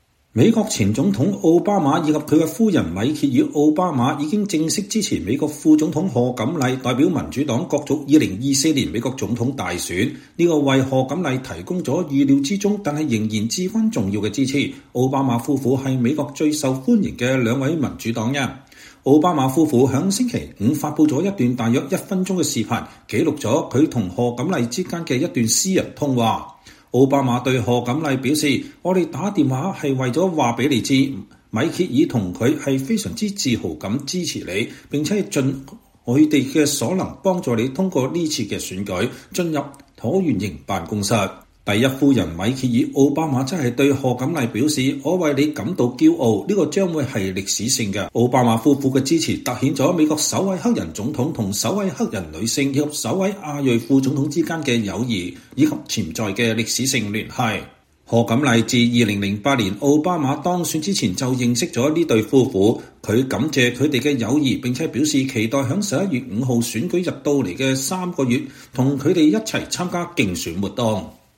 奧巴馬夫婦在星期五(7月26日)發布了一段大約1分鐘的視頻，記錄了他們與賀錦麗之間的一段私人通話。